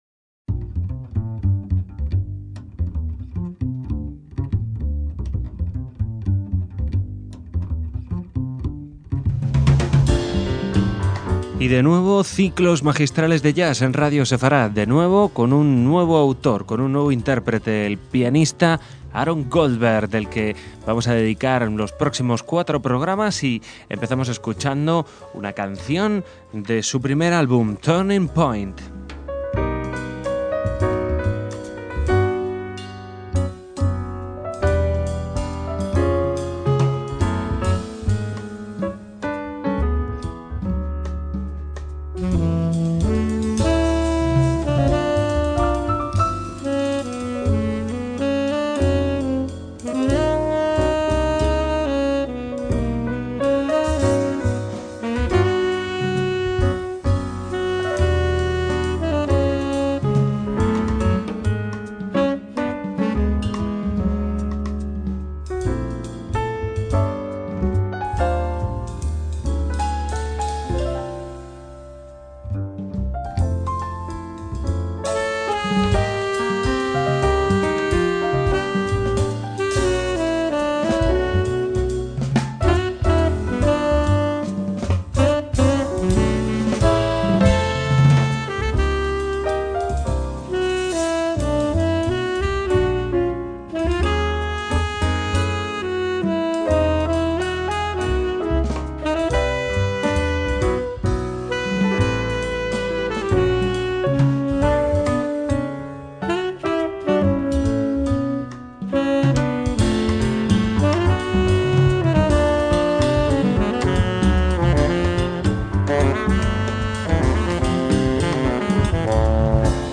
joven pianista